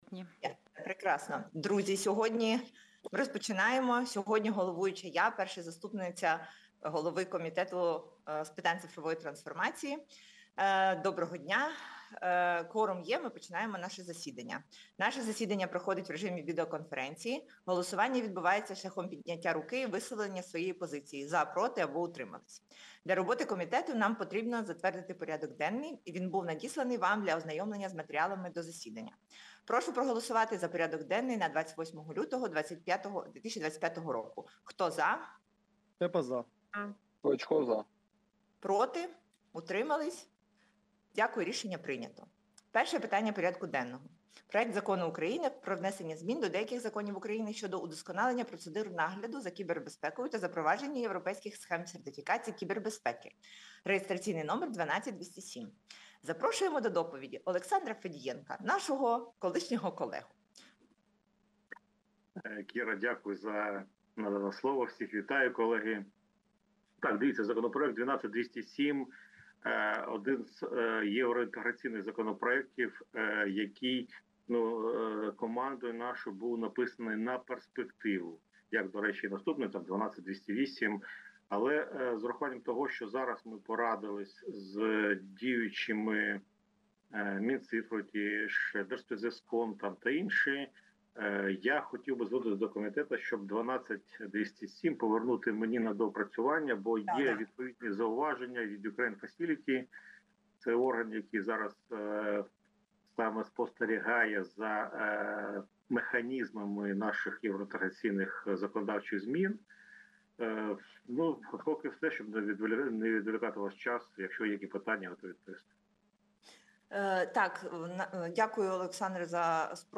Аудіозапис засідання Комітету від 28.02.2025